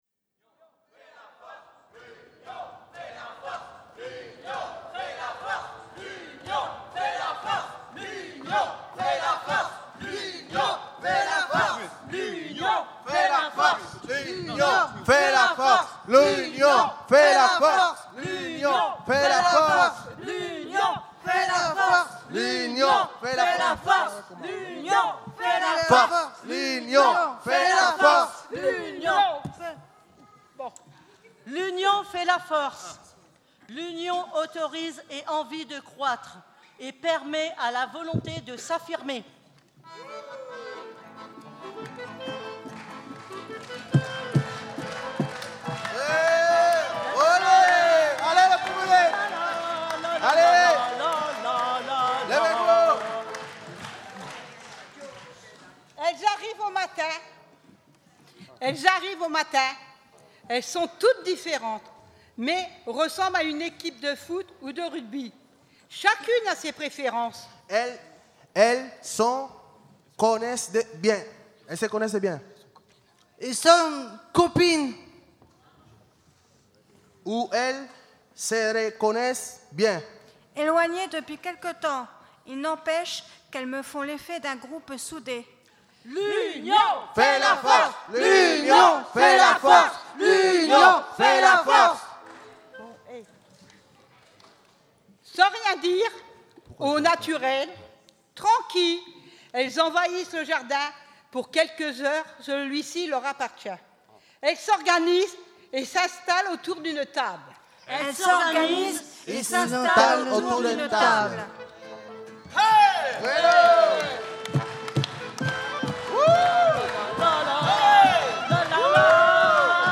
FESTIVALS , LEITURA FURIOSA , LEITURA FURIOSA 2014 , TEXTES DE LEITURA FURIOSA 2014 lectures audio , Leitura Furiosa , Leitura Furiosa 2014 permalien